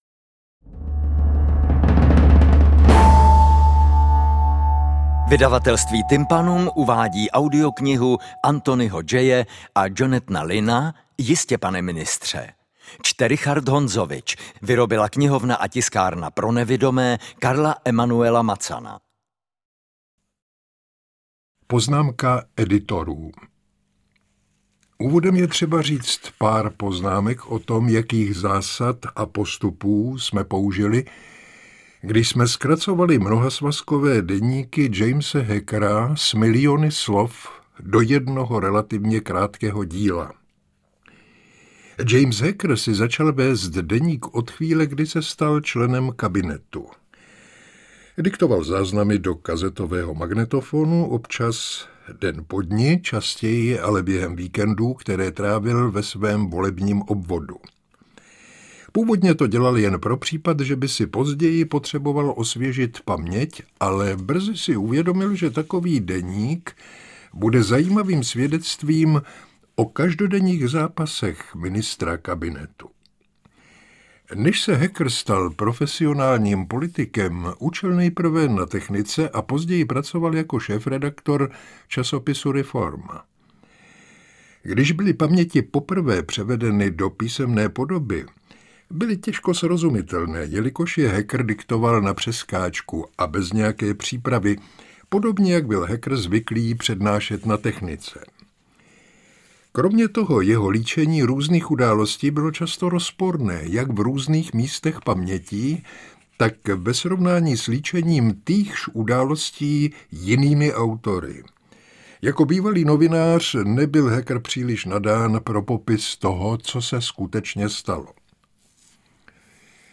V této audioknize sledujeme kariérní postup britského politika Jamese Hackera až k postu nejvyššímu - premiérskému.